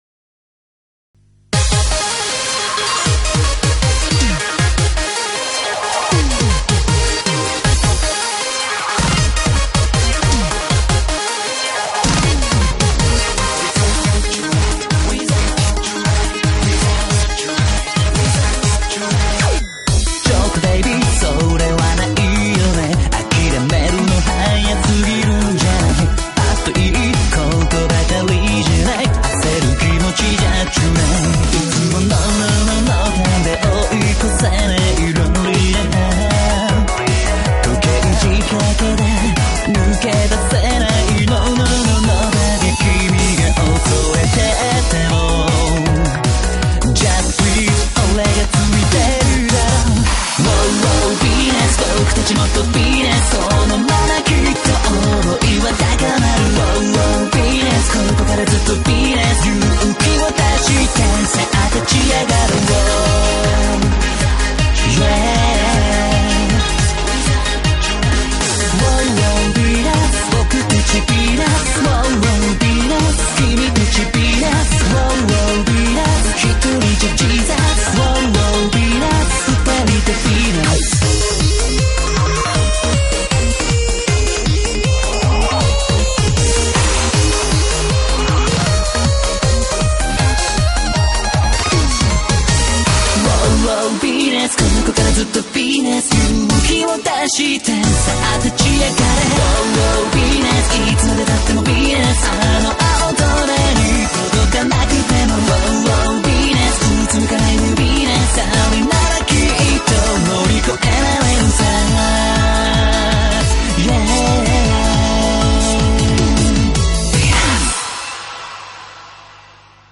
BPM157
Audio QualityPerfect (Low Quality)